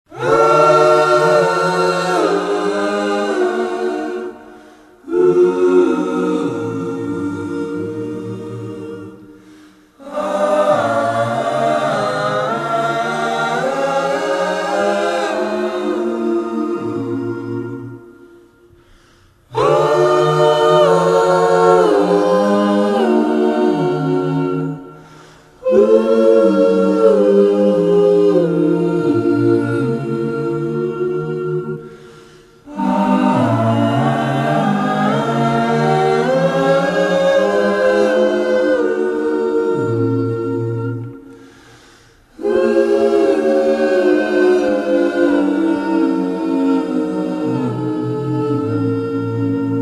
ALL TRACKS DIGITALLY REMASTERED